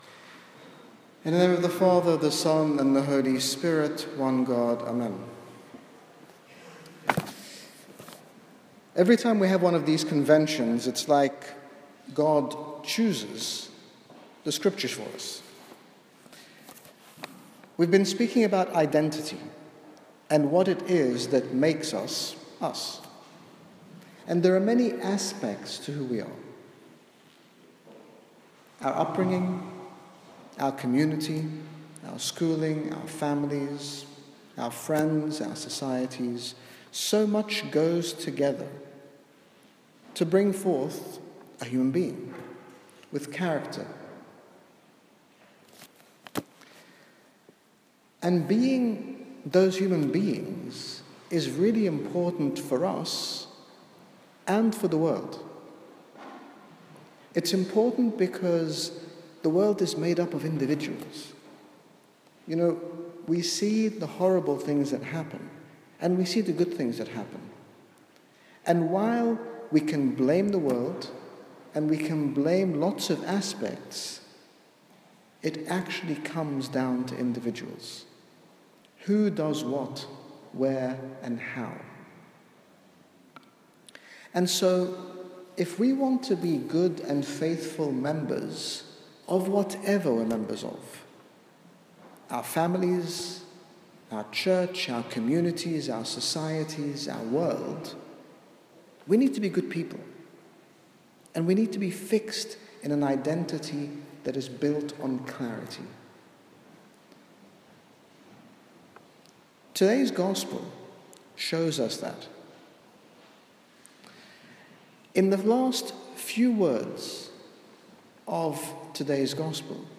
In this sermon, His Grace Bishop Angaelos, General Bishop of the Coptic Orthodox Church in the United Kingdom, speaks to us about the importance of having an identity firmly rooted in the Image and Likeness of God within us, highlighting the influence of our environments, friendships, relationships and daily choices.
Where do we find our identity - St George sermon.mp3